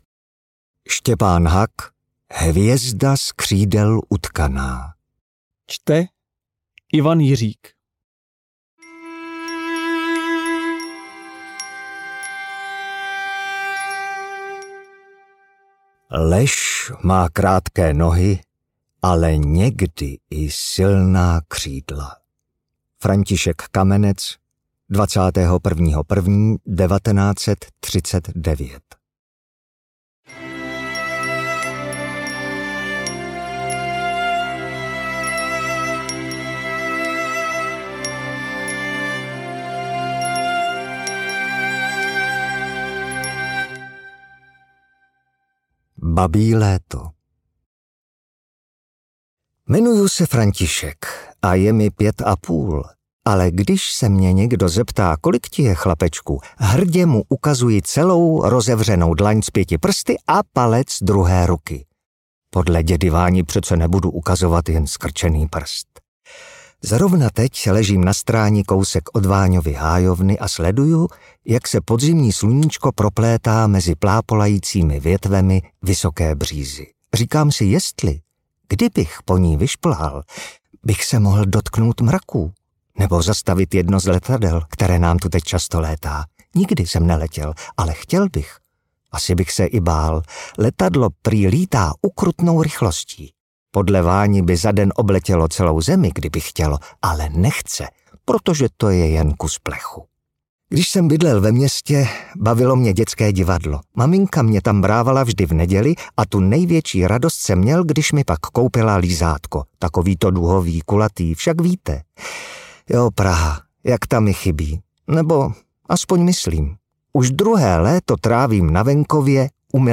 Hvězda z křídel utkaná audiokniha
Ukázka z knihy